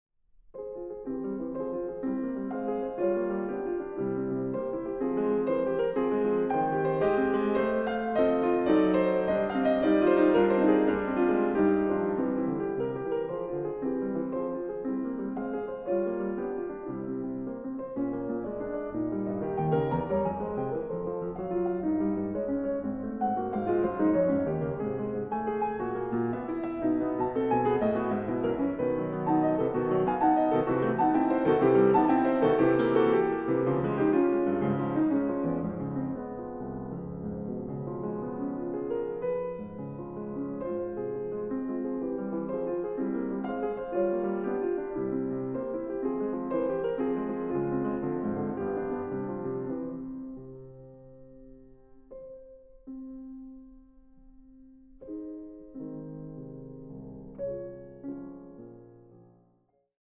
Piano
Intermezzo. Allegretto un poco agitato